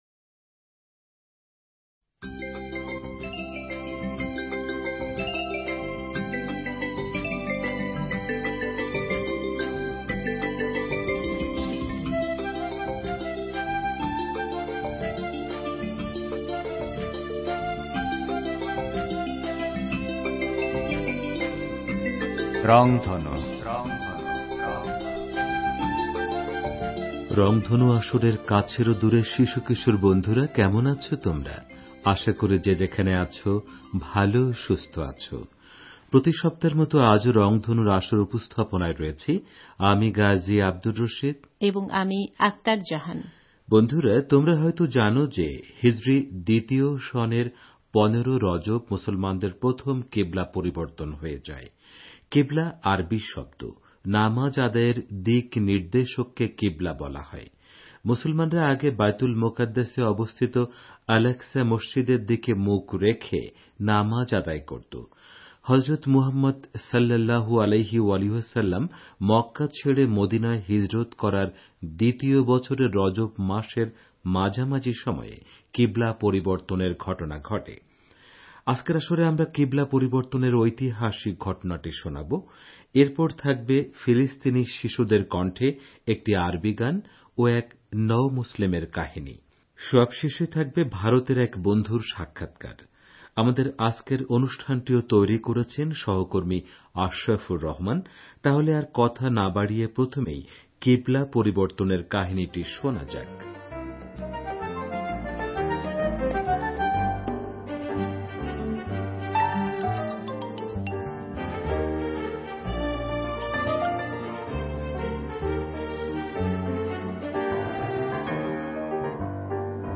রংধনুর এ পর্বে আমরা কিবলা পরিবর্তনের ঐতিহাসিক ঘটনাটি শোনাব। এরপর থাকবে ফিলিস্তিনি শিশুদের কণ্ঠে একটি আরবী গান ও এক নওমুসলিমের কাহিনী। সবশেষে থাকবে ভারতের এক বন্ধুর সাক্ষাৎকার।